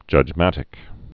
(jŭj-mătĭk) also judg·mat·i·cal (-ĭ-kəl)